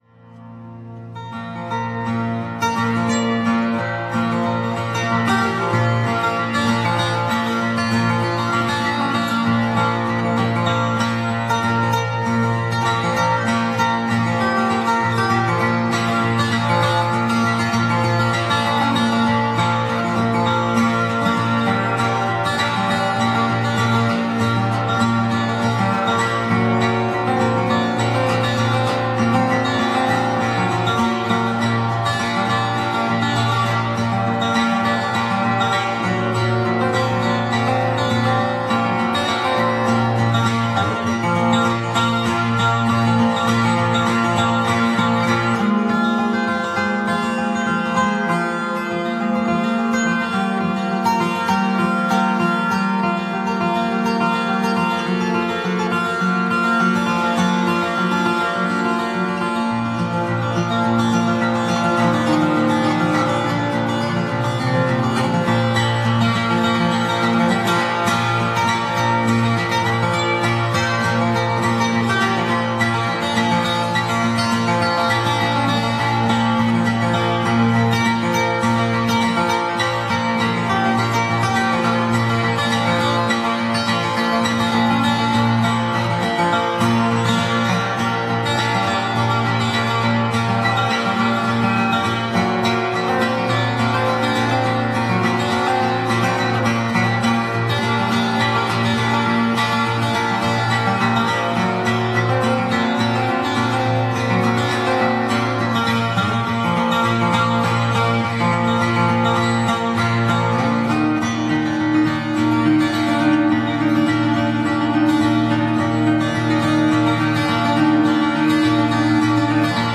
'New Music for Steel String Guitar'